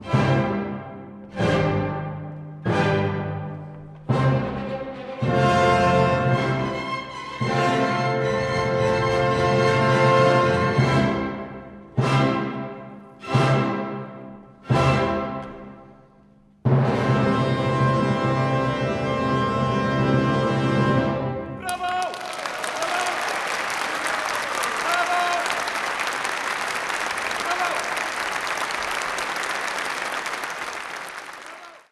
Brahms_mono.wav